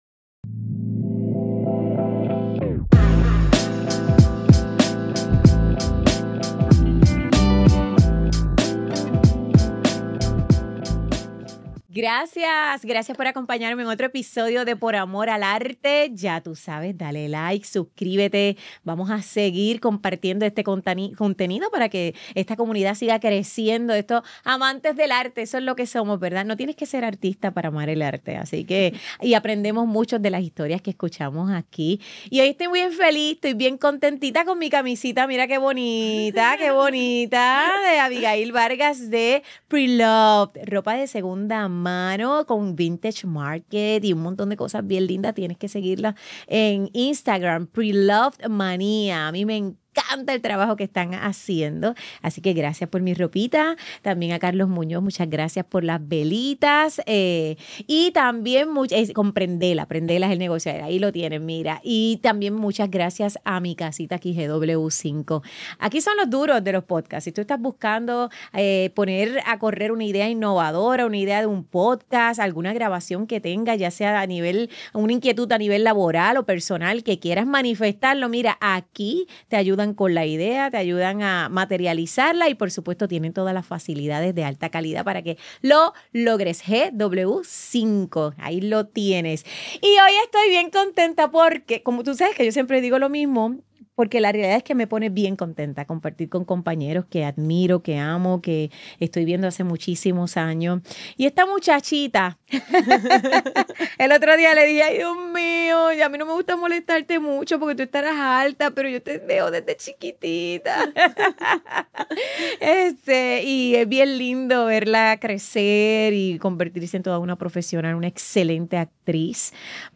¡No te pierdas esta entrevista! Grabado en GW-Cinco Studio para GW5 Network Stylist